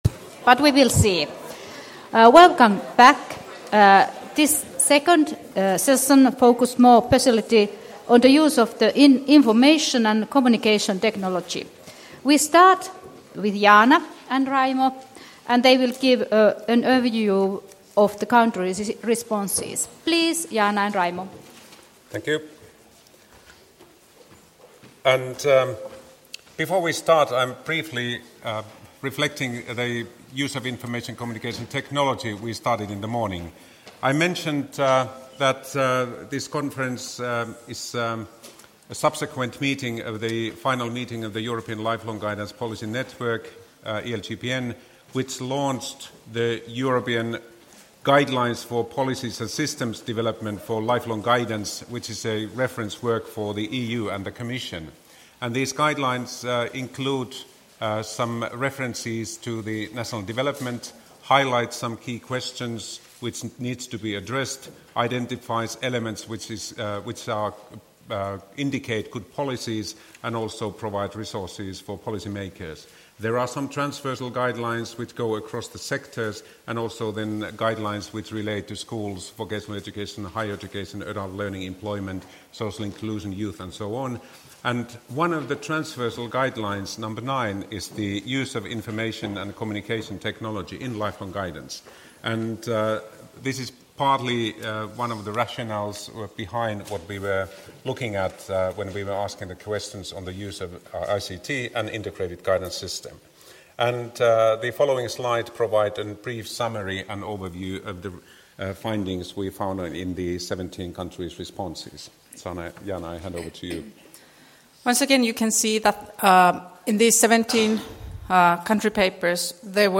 “Ohjaamo” One-Stop Guidance Centers: Developing Policy and Practice for Co-careering - National Lifelong Guidance Policy Seminar 26.11.2015 Jyväskylä.